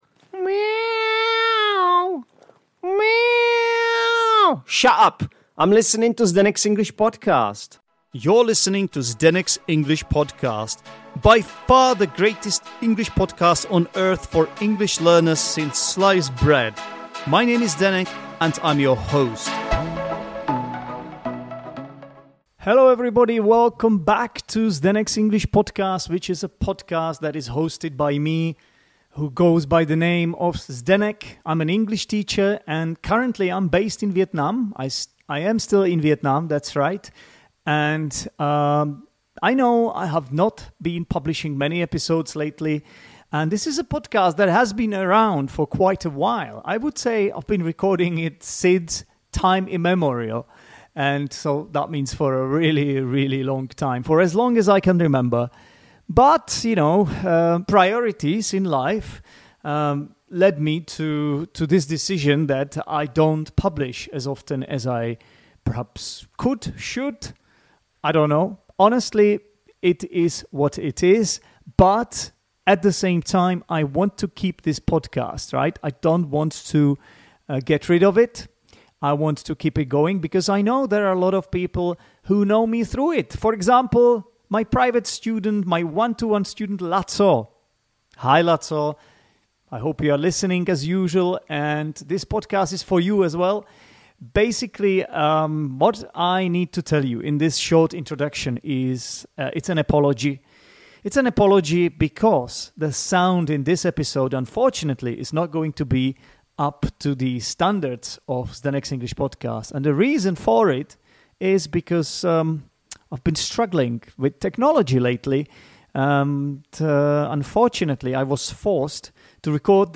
This time, it's a more casual catch-up — we talk about how life has been since we last spoke, dive into thoughts on London, reflect on the state of the world, and have a good old ramble about all sorts of things. Just two friends chatting and seeing where the conversation takes us.